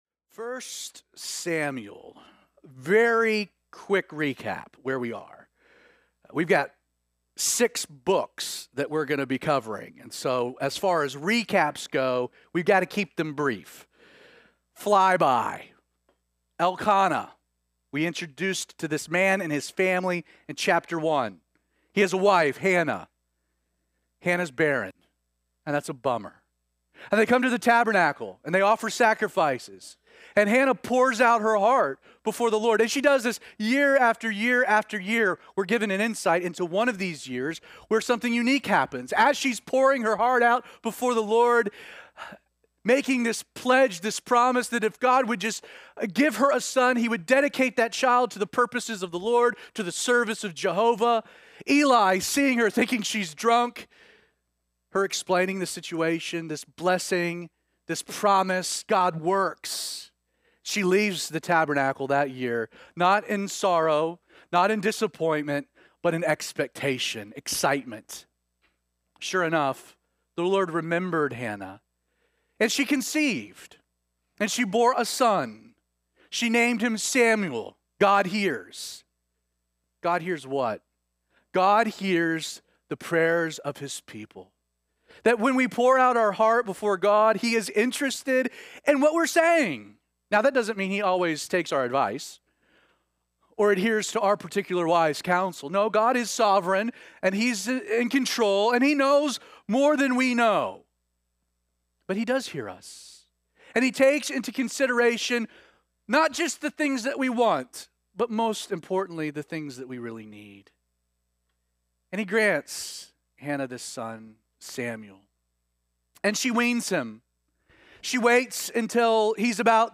These message are recorded live at Calvary316 on Sunday mornings and posted later that afternoon.